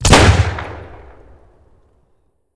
Shotgun1_Shoot 01.wav